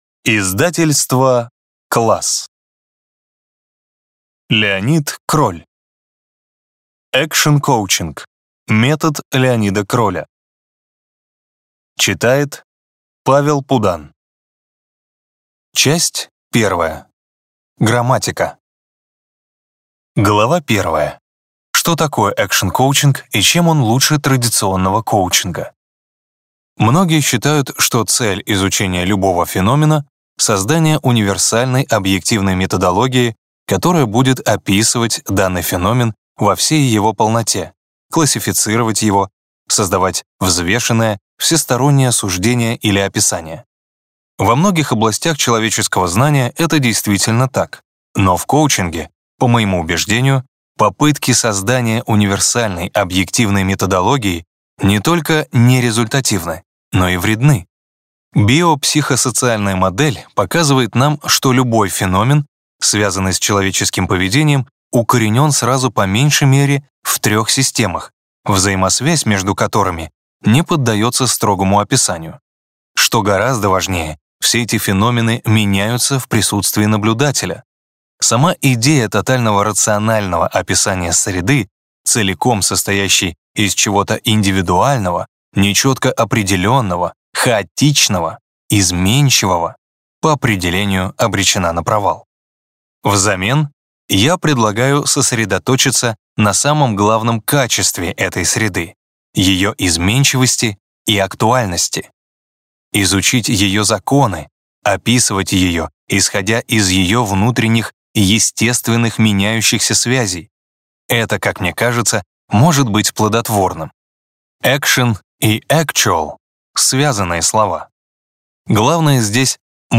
Аудиокнига Экшн-коучинг. Метод Л. Кроля | Библиотека аудиокниг